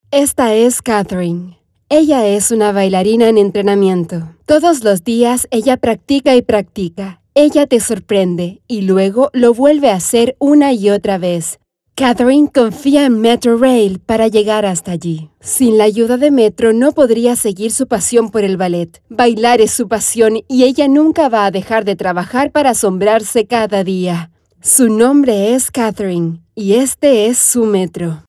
I can be fresh, youthful, mysterious and sensual with my voice. I have a close and welcoming voice, honest but also playful and funny!
Sprechprobe: Industrie (Muttersprache):